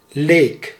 Summary Description Nl-leek.ogg Dutch pronunciation for " leek " — male voice.
Nl-leek.ogg